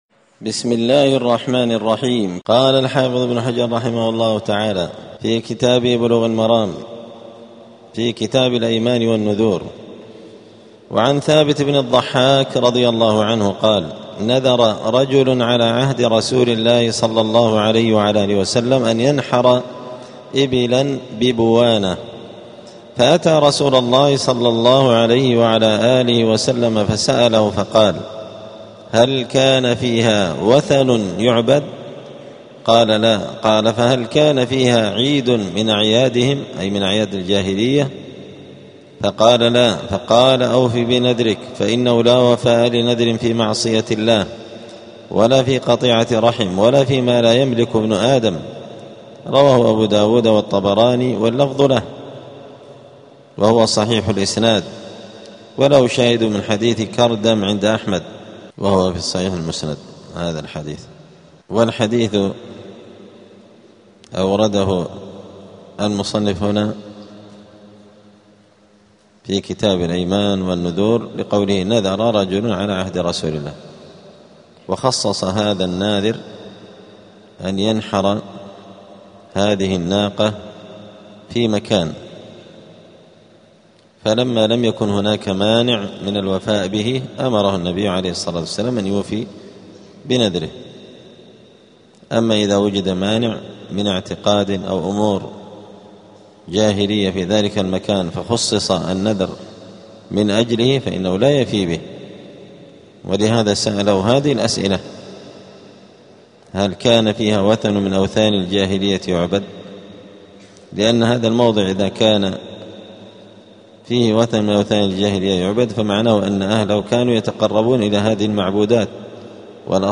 *الدرس الثامن عشر (18) {ﻧﺬﺭ اﻟﻤﻜﺎﻥ اﻟﻤﻌﻴﻦ}*